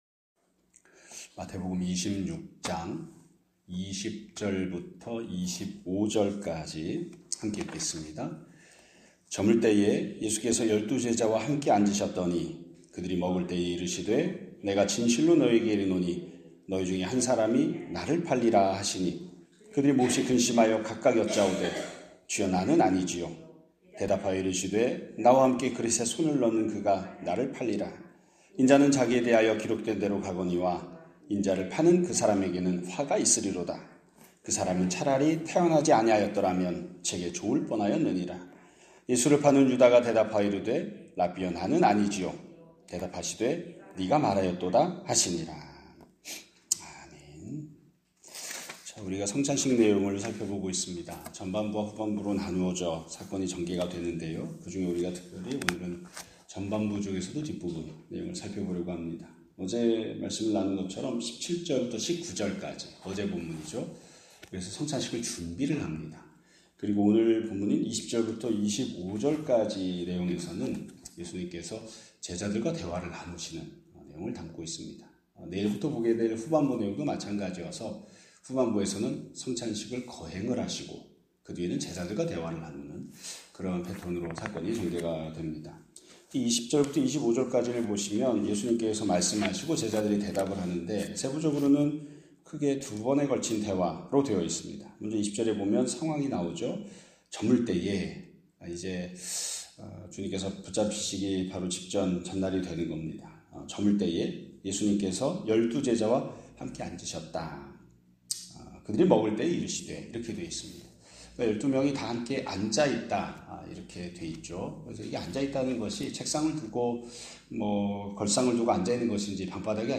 2026년 3월 31일 (화요일) <아침예배> 설교입니다.